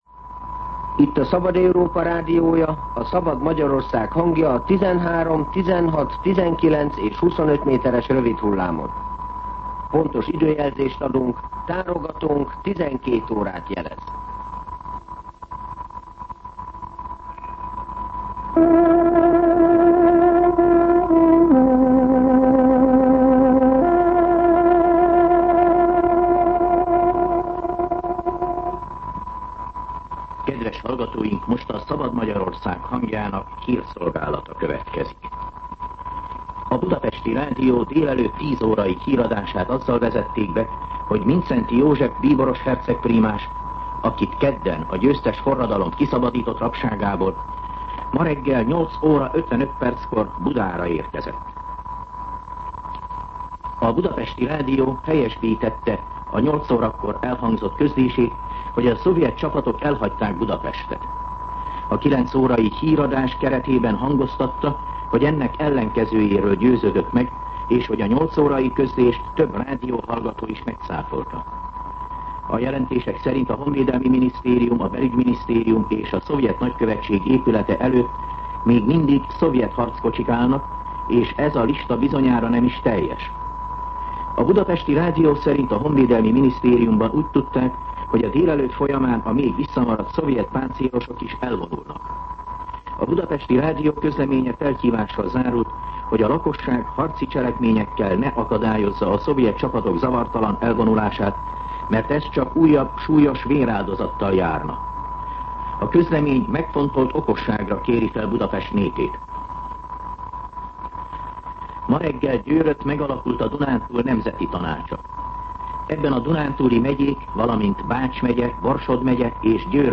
12:00 óra. Hírszolgálat